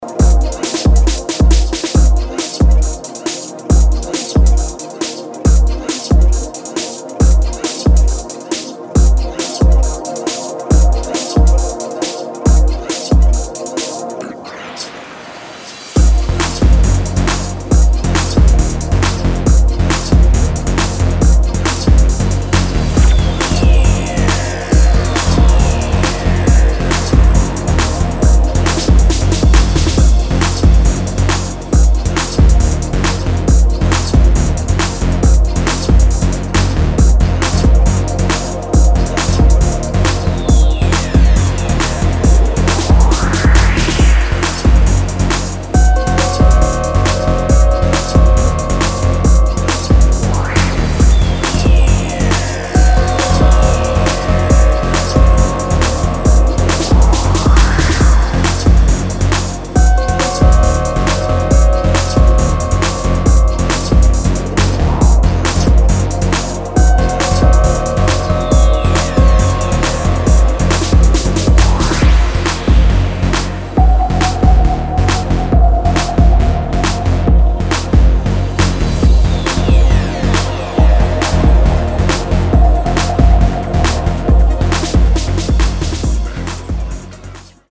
Stunning Electro cuts
mesmerizing remix